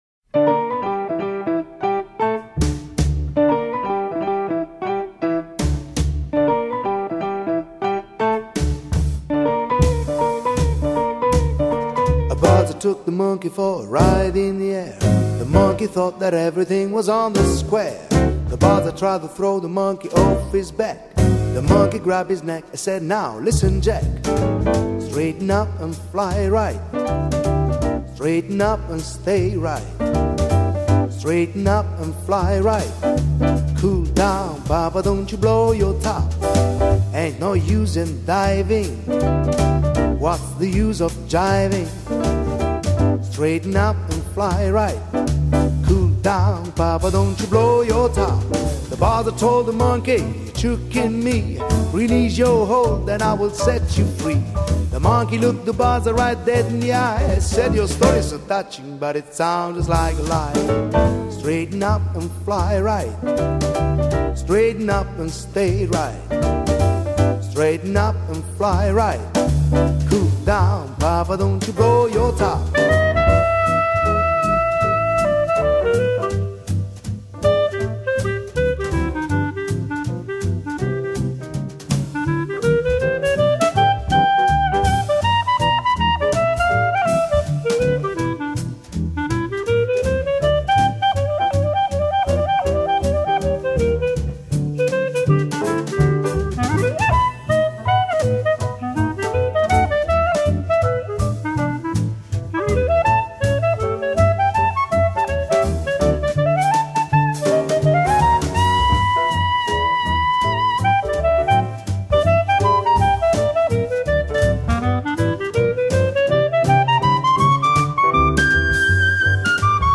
类别： 爵士
主奏乐器：钢琴
一群热爱爵士的乐手以不同的爵士乐风，烘托城市的美妙炫丽，用另一种角度观看城市风情~~~